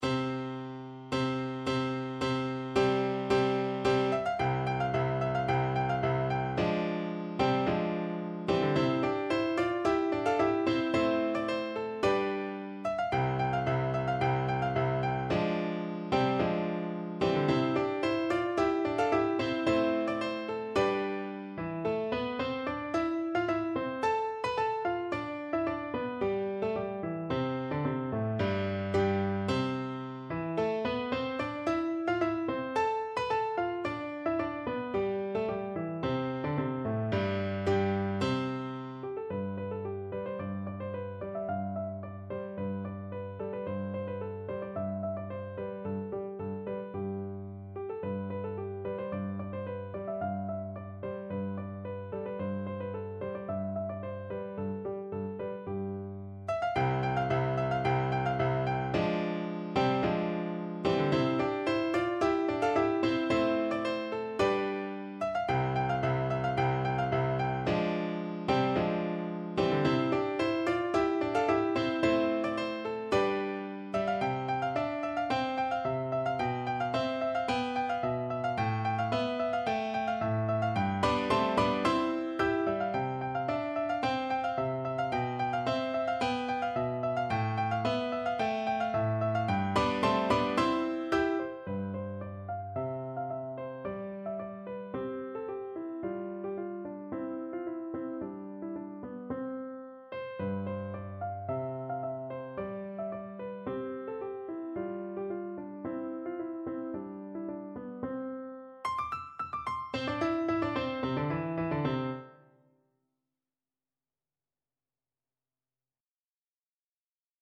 = 110 Allegro di molto (View more music marked Allegro)
2/2 (View more 2/2 Music)
Classical (View more Classical Flute Music)